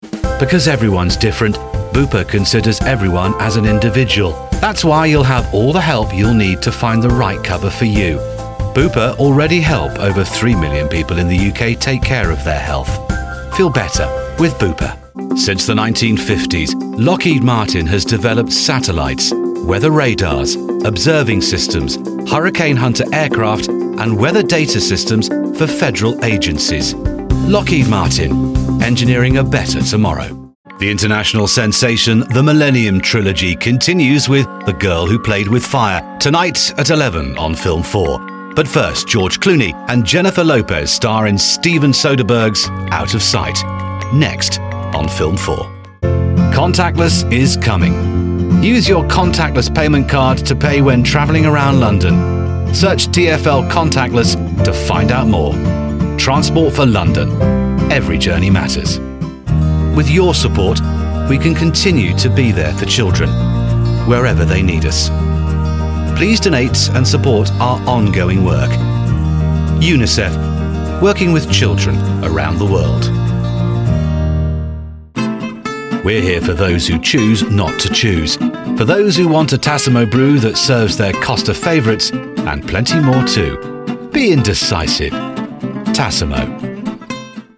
Chaleureux
Autoritaire
Sombre